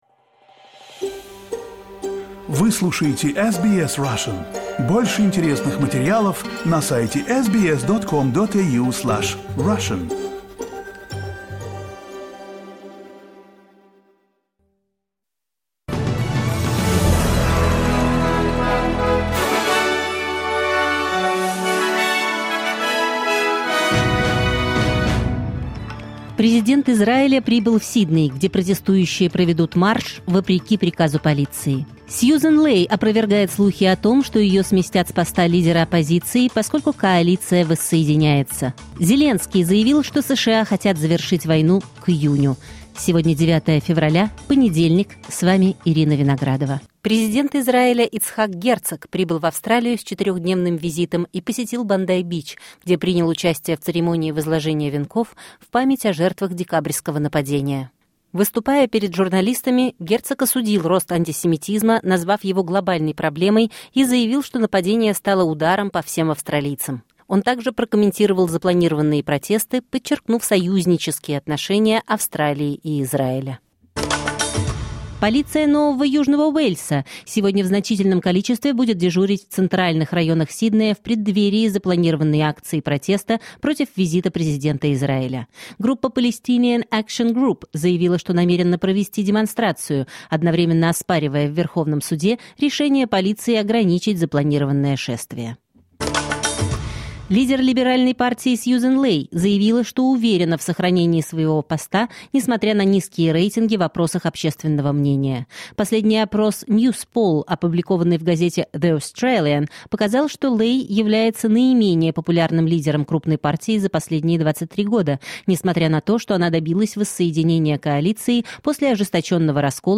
Новости SBS на русском языке — 9.02.2026